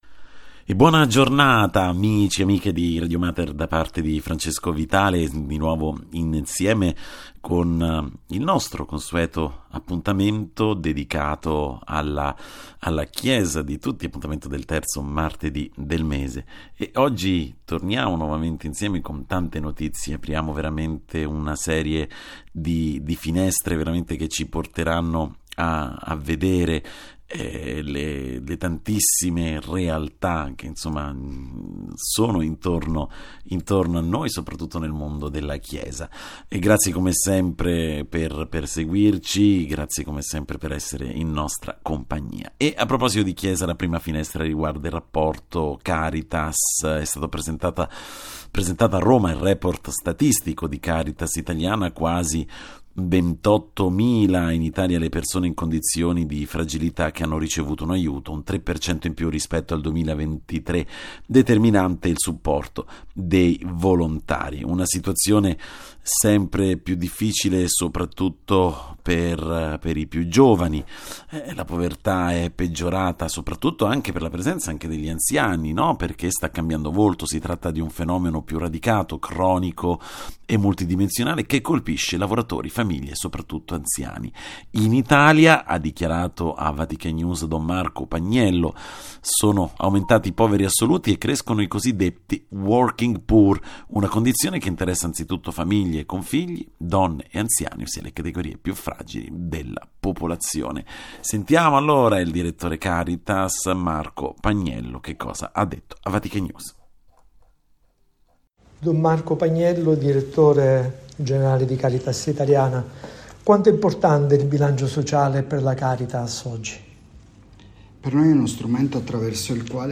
Ospiti in studio nella trasmissione speciale andata in onda su Radio Mater